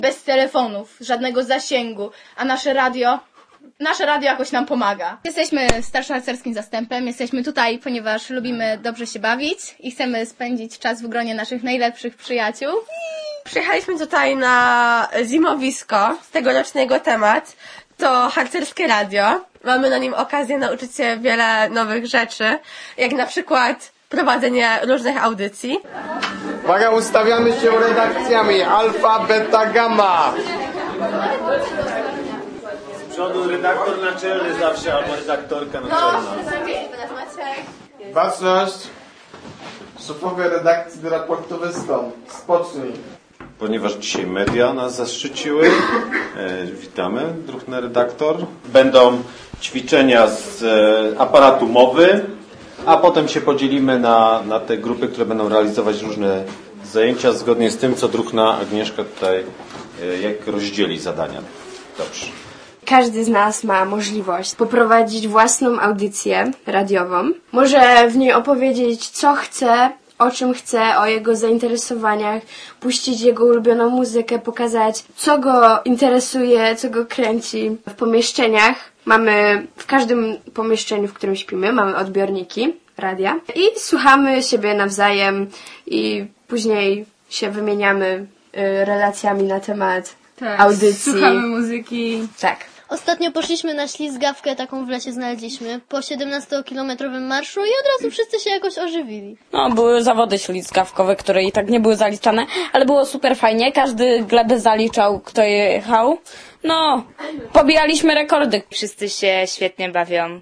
Zorganizowali zimowy biwak, a na nim - warsztaty radiowe. Wcześniej zbudowali nadajnik o zasięgu kilkudziesięciu metrów, no i teraz prowadzą harcerską stację. Na zimowy biwak radiowy - prosto z radia - pojechała nasza reporterka.